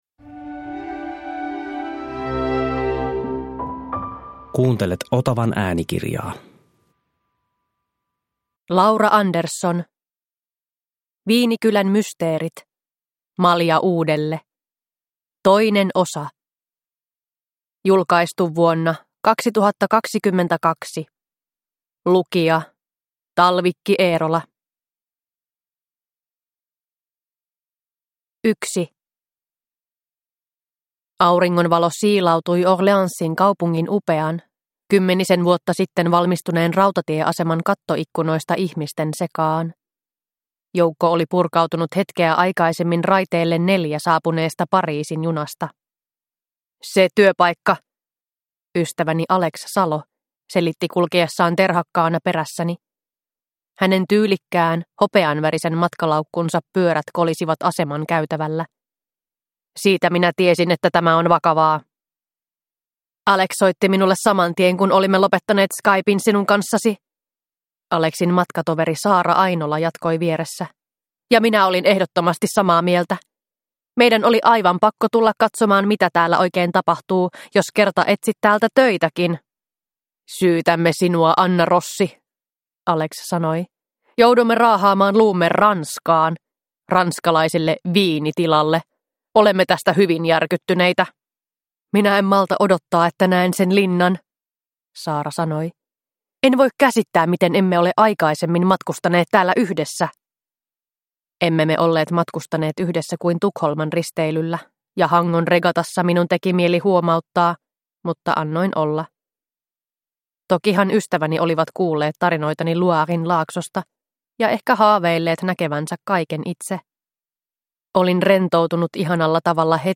Malja uudelle 2 – Ljudbok – Laddas ner